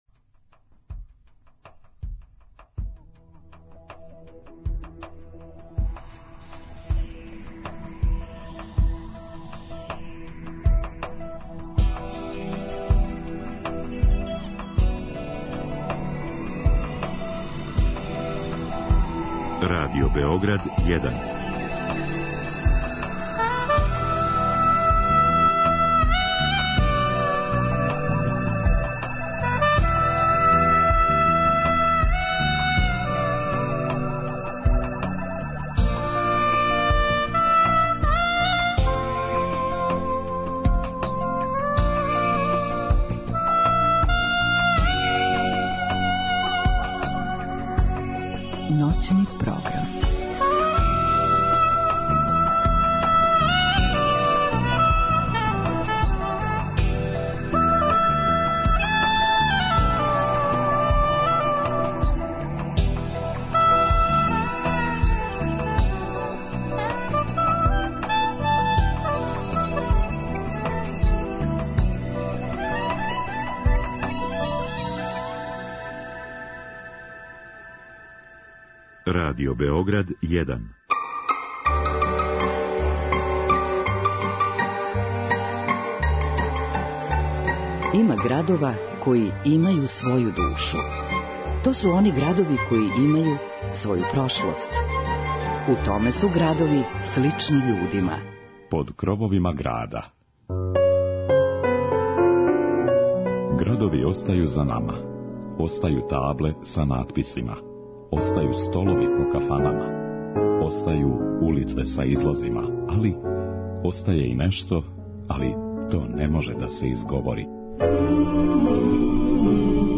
Ове ноћи прошетаћемо кроз Војводину и представити тамбурашке оркестре из разних крајева.